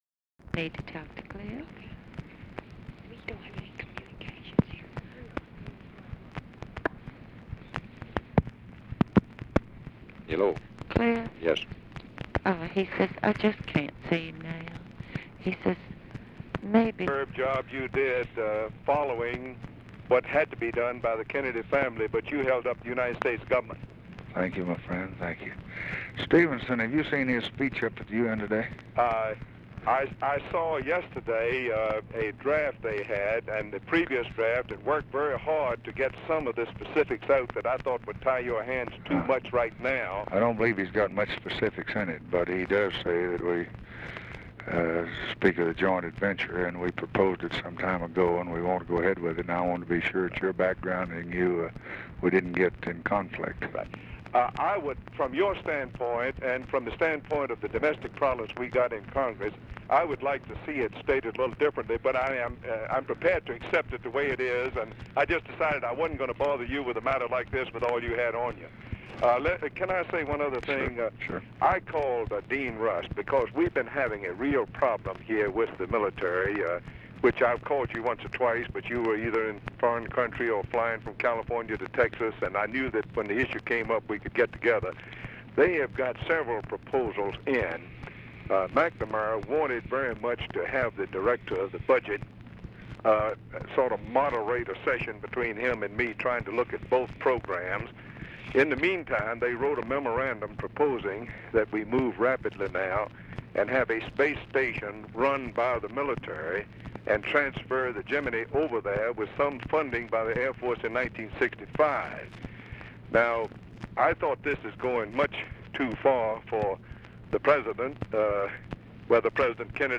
Conversation with JAMES WEBB, November 26, 1963
Secret White House Tapes